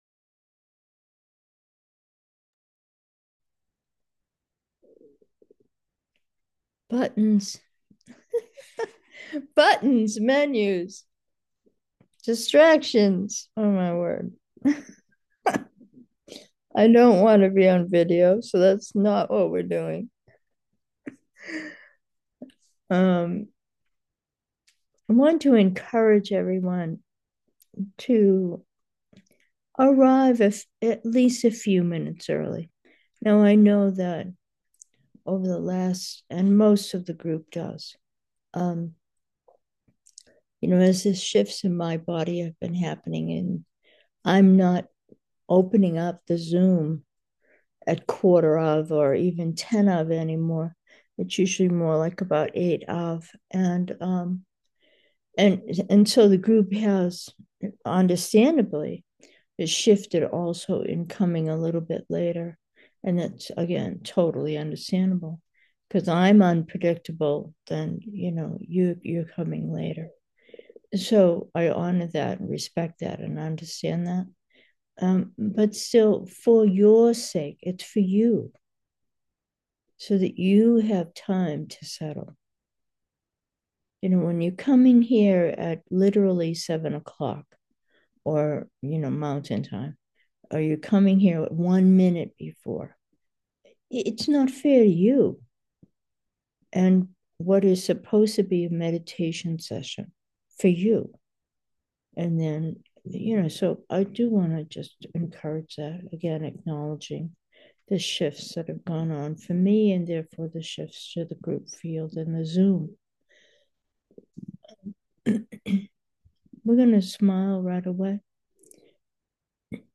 Meditation: ephemeral